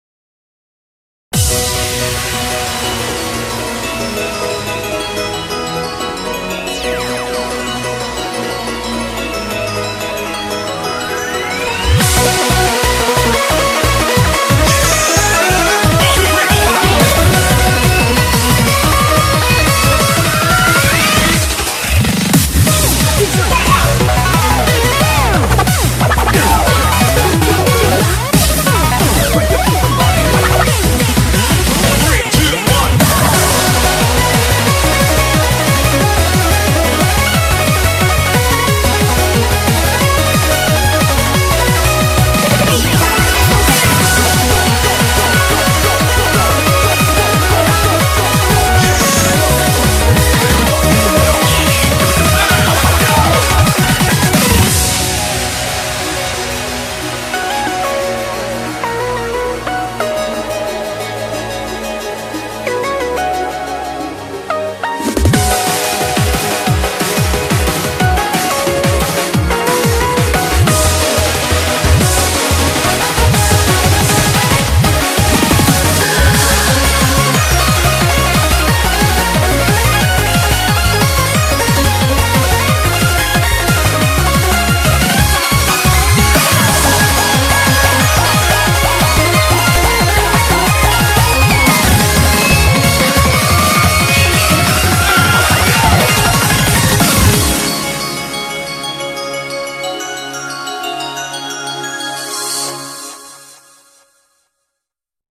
BPM180
high-speed remix